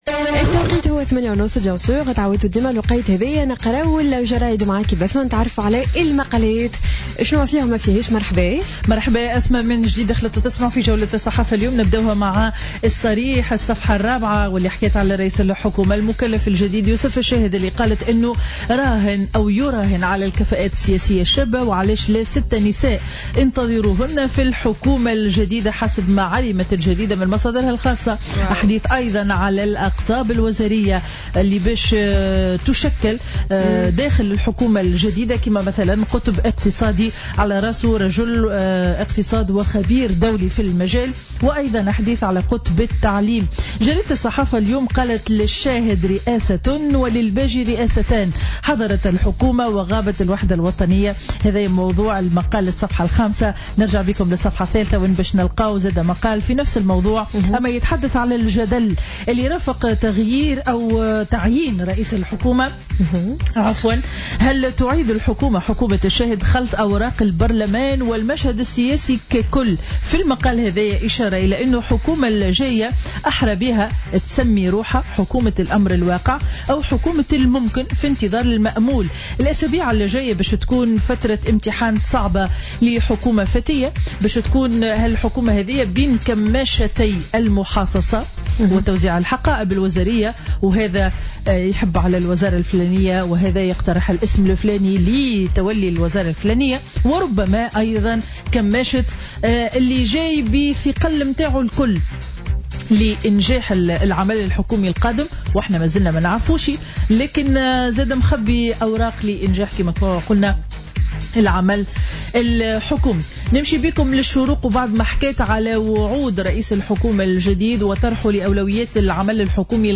Revue de presse du jeudi 4 août 2016